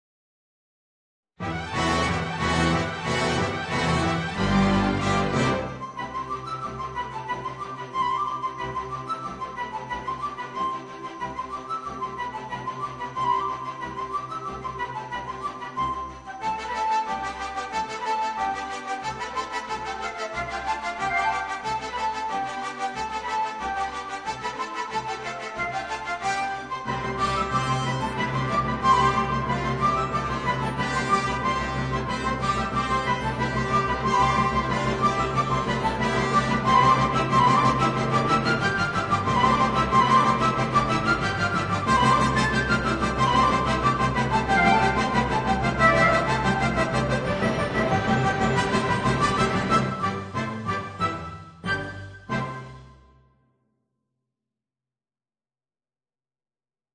Voicing: Clarinet and Orchestra